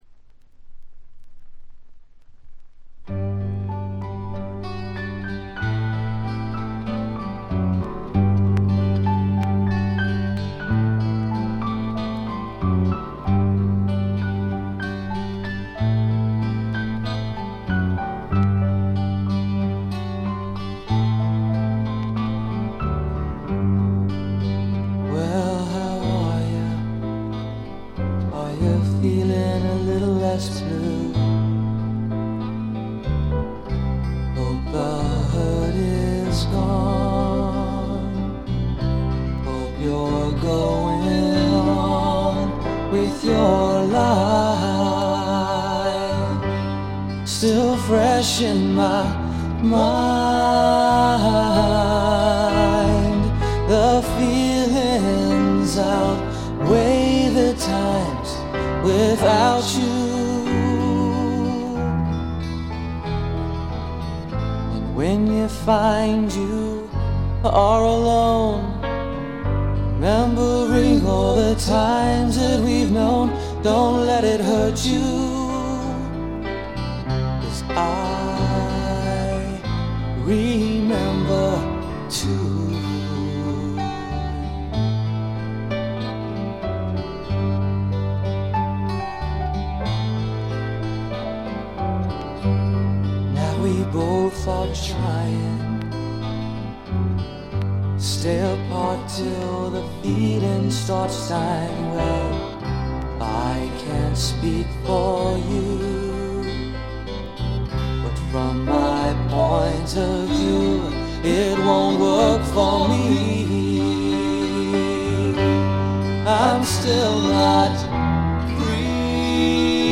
気づいたのはA2冒頭の軽いチリプチ程度。
内容はポップでAOR的なサウンドが印象的なシンガー・ソングライター・アルバム。
試聴曲は現品からの取り込み音源です。